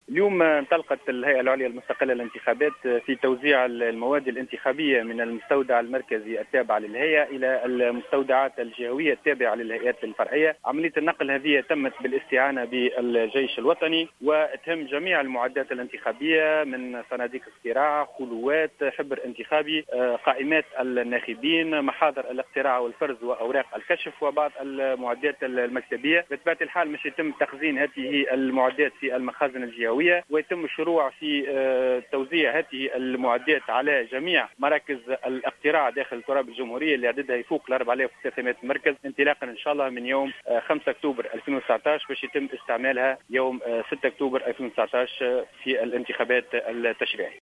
أكد عضو مجلس الهيئة العليا المستقلة للانتخابات فاروق بوعسكر اليوم الأحد في تصريح لـ "الجوهرة اف أم" أنه انطلقت صباح اليوم بالمستودع البلدي بالوردية، عملية نقل المواد الانتخابية الخاصة بالانتخابات التشريعية 2019 إلى مقرات الهيئات الفرعية.